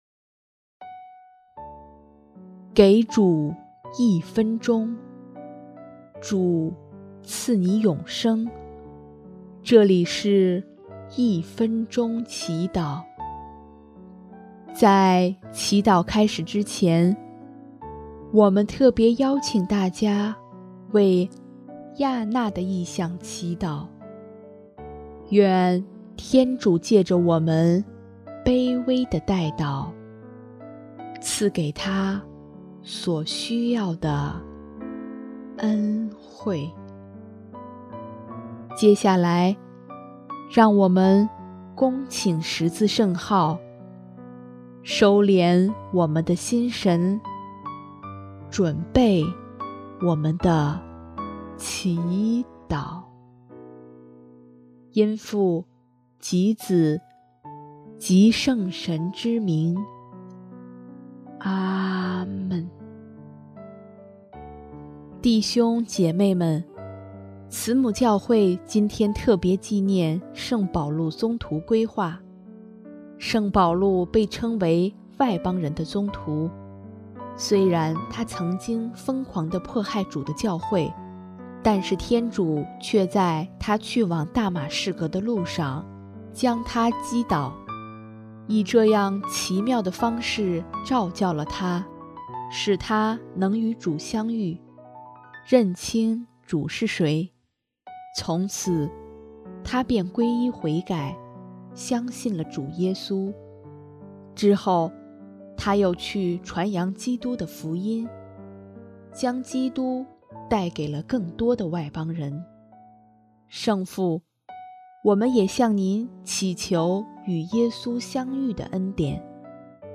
【一分钟祈祷】|1月25日 摔下来的皈依，外邦人的宗徒